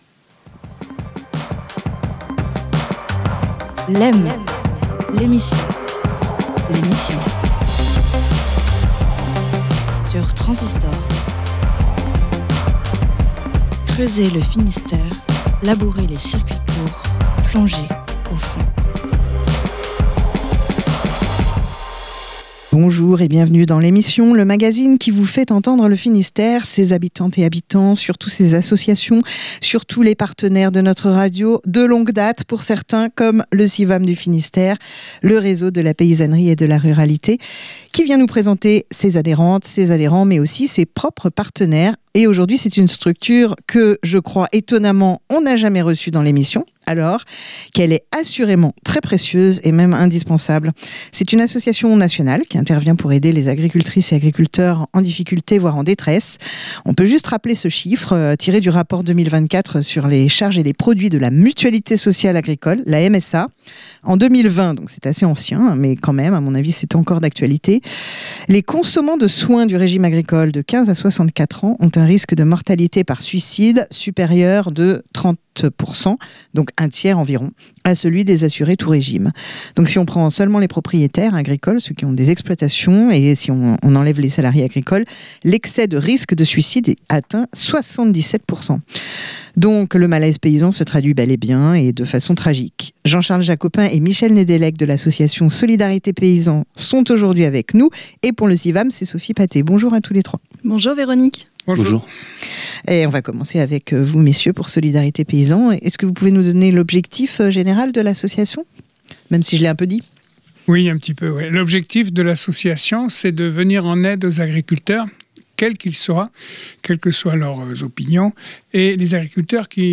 Une émission mensuelle de Lem, la quotidienne, réalisée en partenariat avec le réseau Civam du Finistère